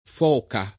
Foca [ f 0 ka ]